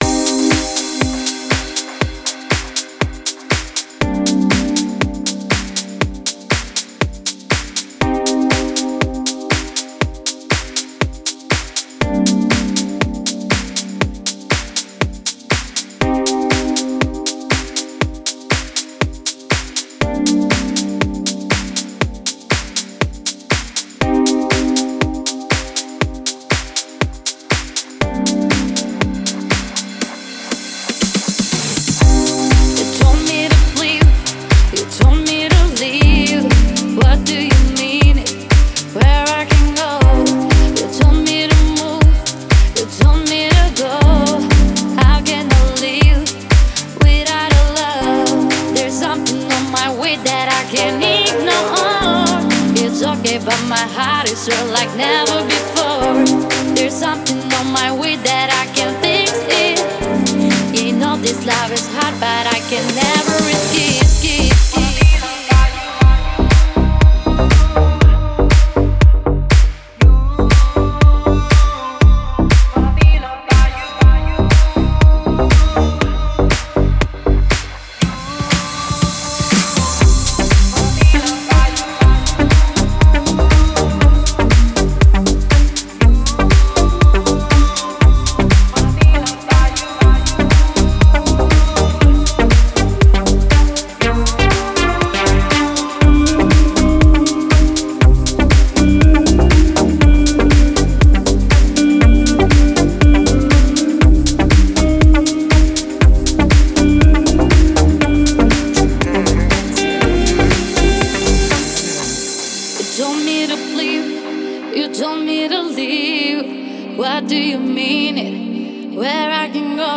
Дип Хаус музыка